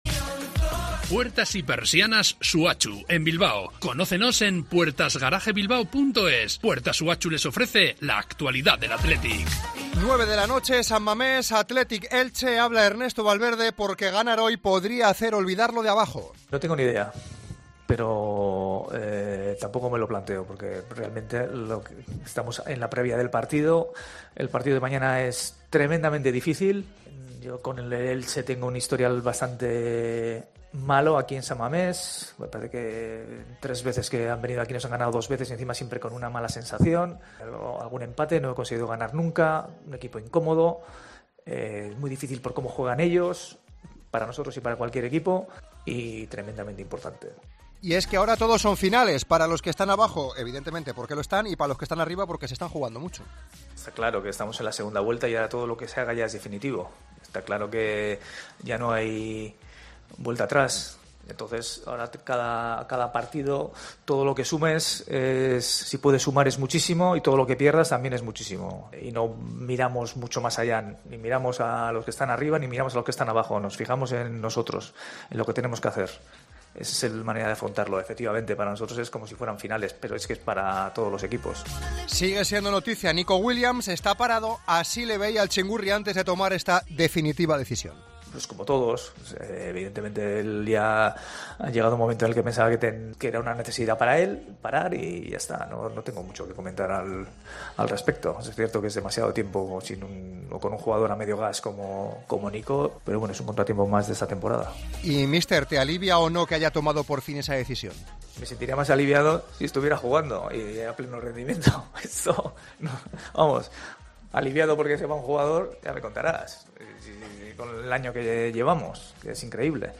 El entrenador del Athletic Club, Ernesto Valverde, ha comparecido en la previa del partido contra el Elche en San Mamés, un encuentro que ha calificado como 'tremendamente importante'.